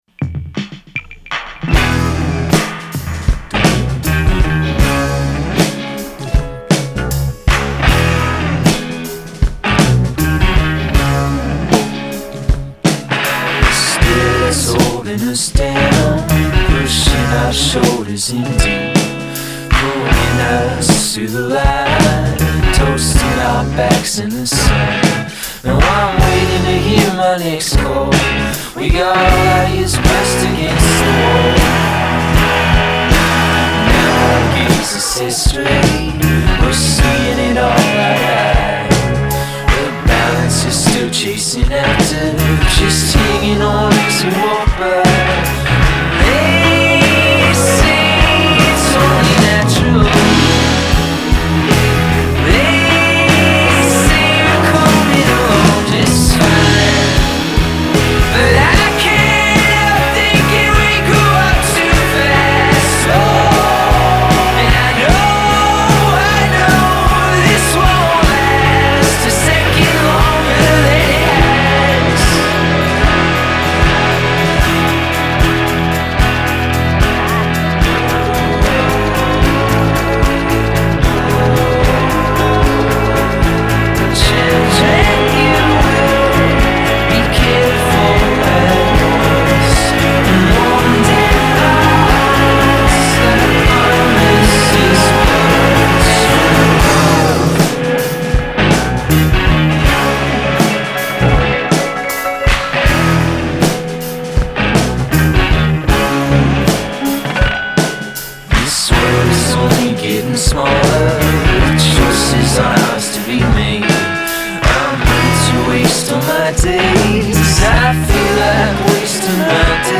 While the music seems amorphous and strange